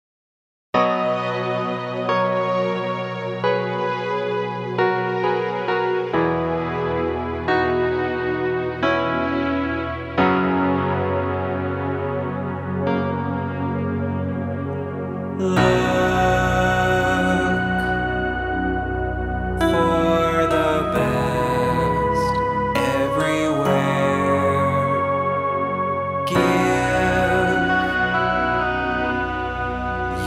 Home > Lullabies